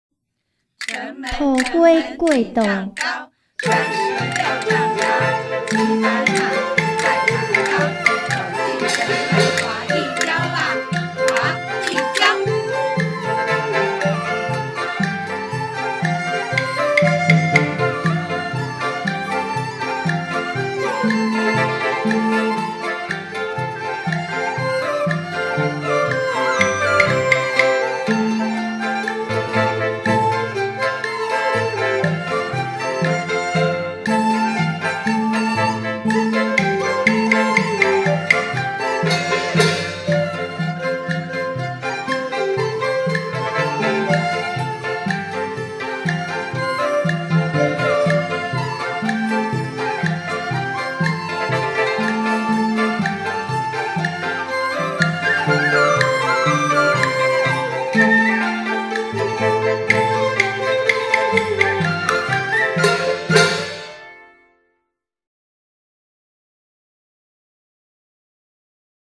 已錄製人聲(VocalA)檔案 音樂(MusicA)檔案 混音後檔案
6. 一般混音是以人聲檔為底，再把音樂檔、音效混入聲音檔